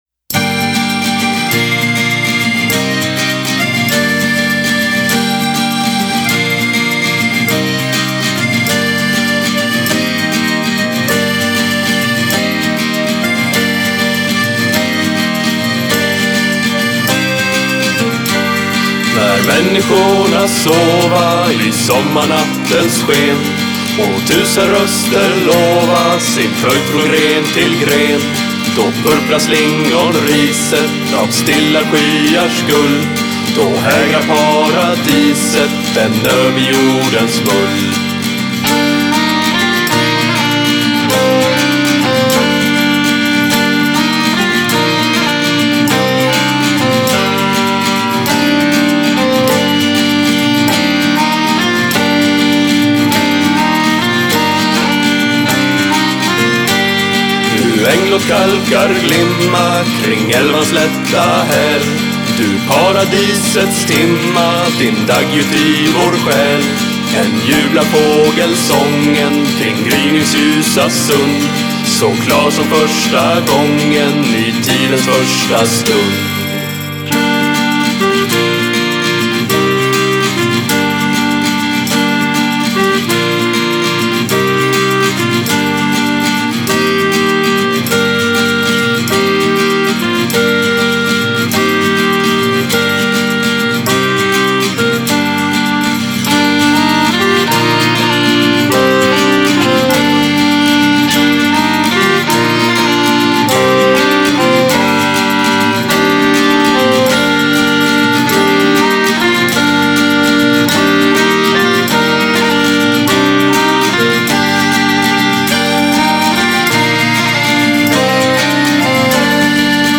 Style: Neofolk